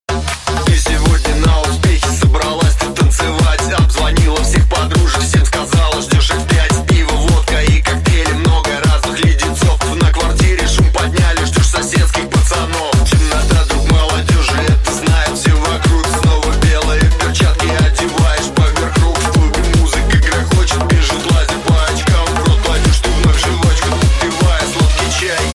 • Качество: 128, Stereo
энергичные
Хардбасс